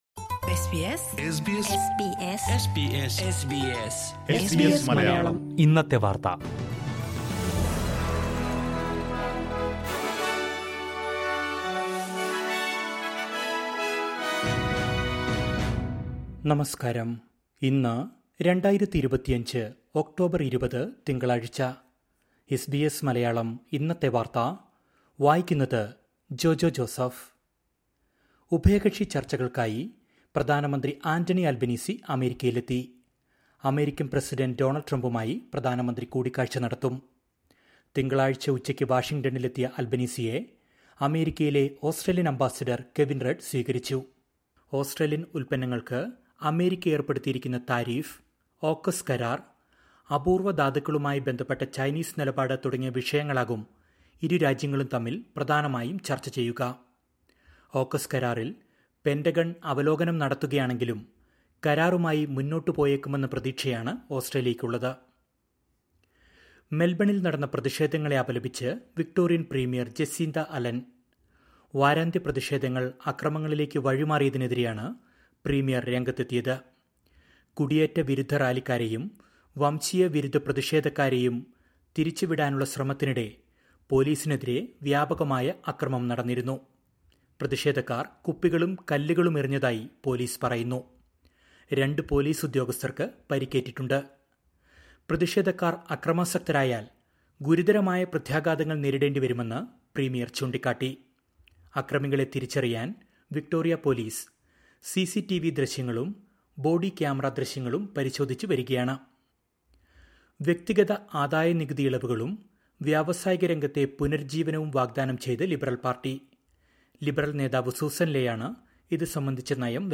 2025 ഒക്ടോബർ 20ലെ ഓസ്ട്രേലിയയിലെ ഏറ്റവും പ്രധാന വാർത്തകൾ കേൾക്കാം...